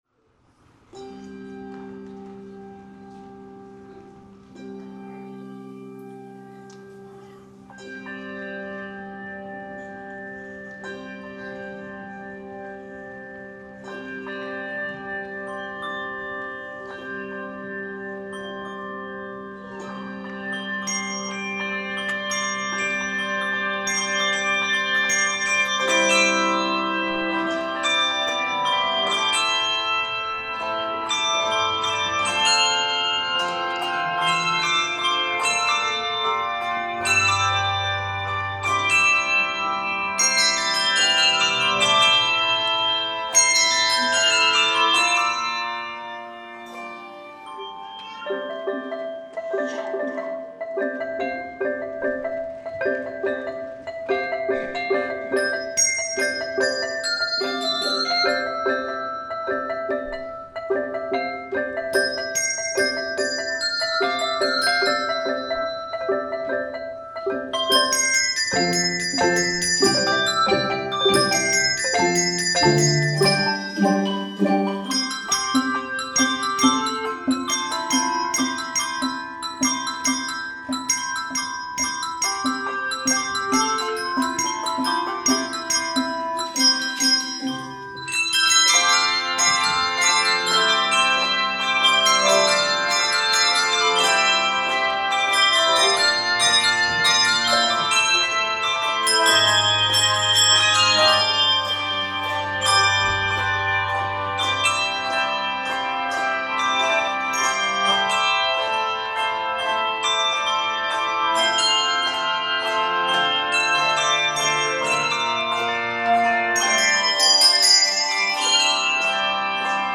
Voicing: Handbells 4-7.5 Octave